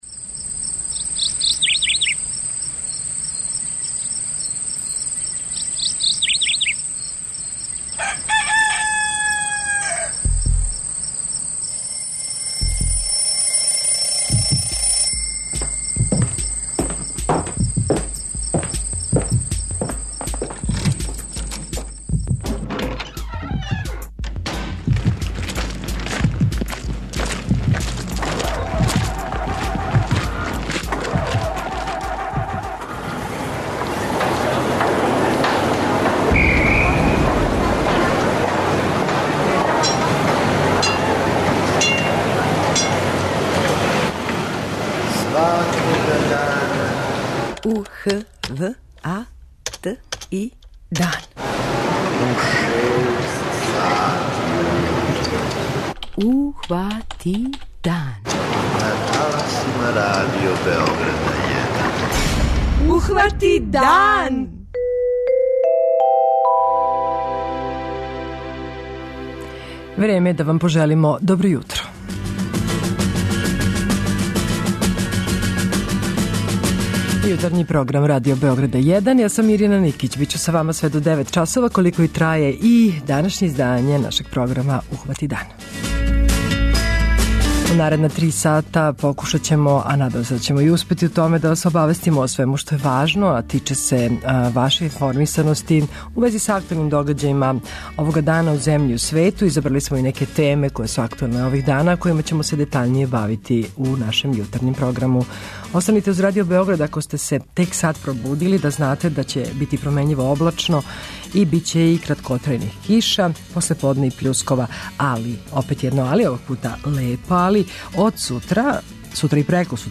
Из садржаја Јутарњег програма издвајамо: